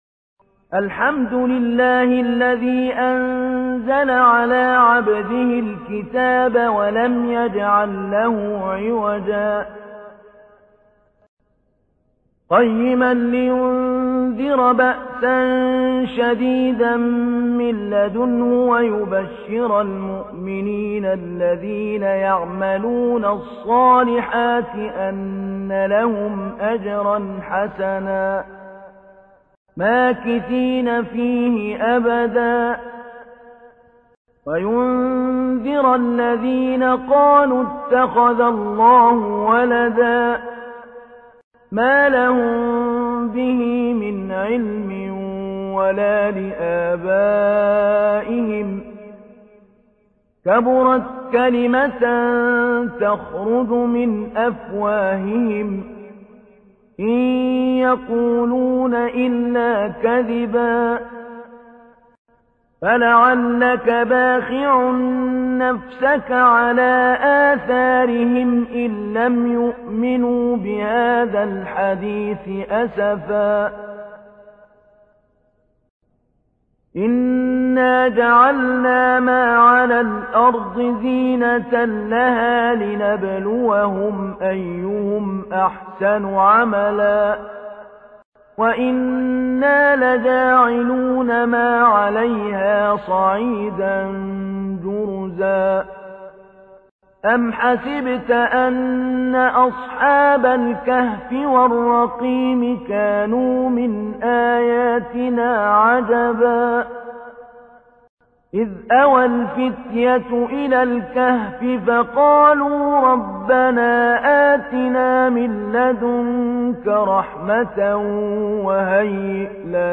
تحميل : 18. سورة الكهف / القارئ محمود علي البنا / القرآن الكريم / موقع يا حسين